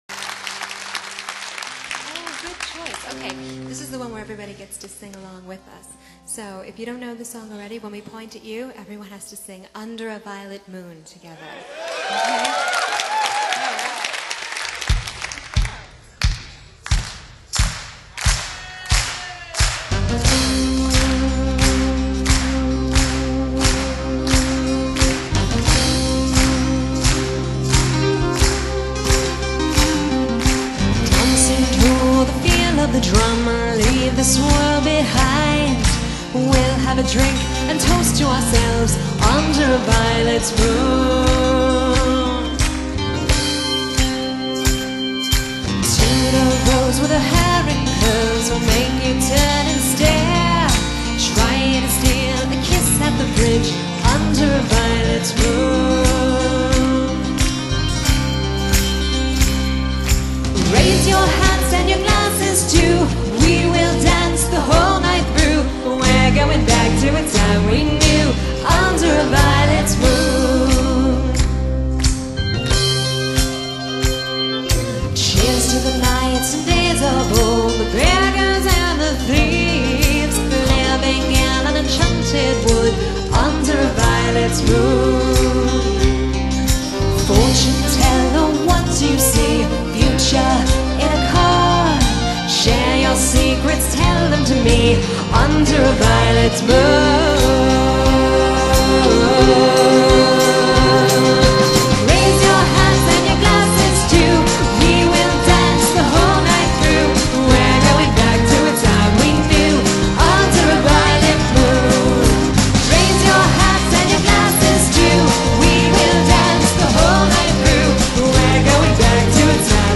Folk Rock / Renaissance